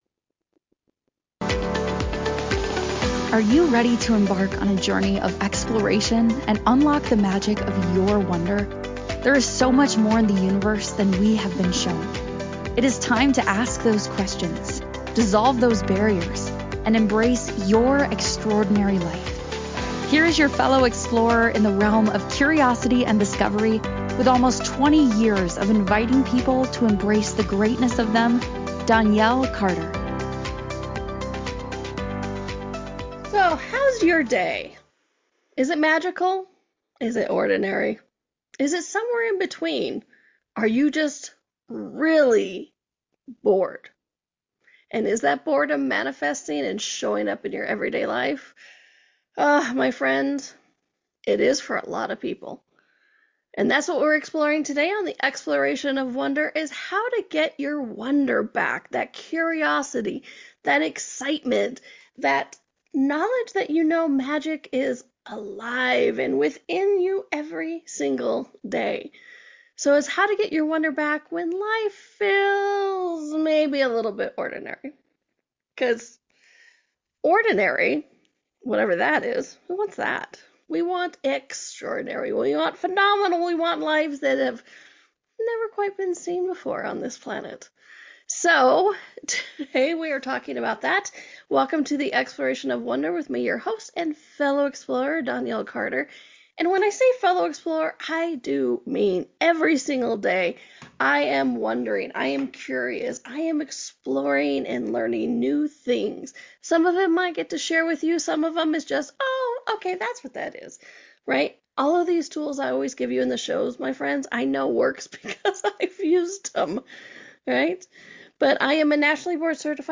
Each episode is a deep-yet-lighthearted conversation about energy, perception, and the magic hiding in plain sight.